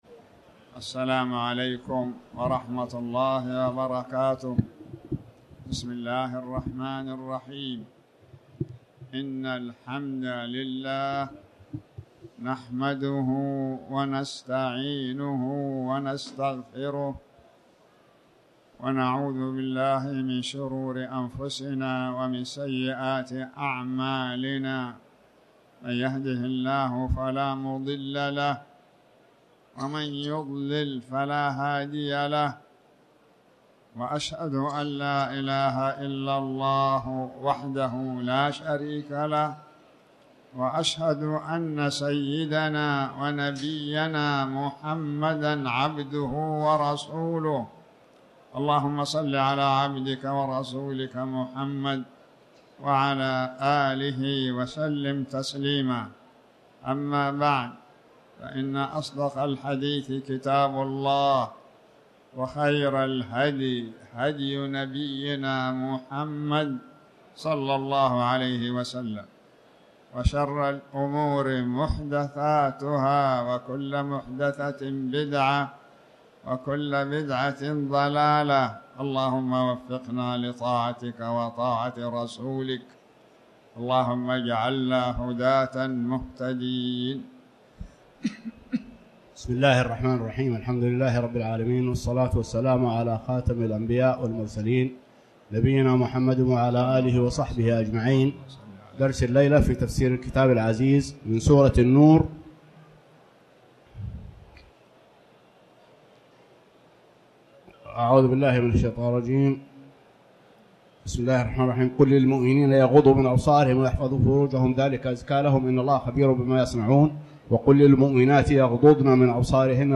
تاريخ النشر ١٧ ربيع الثاني ١٤٤٠ هـ المكان: المسجد الحرام الشيخ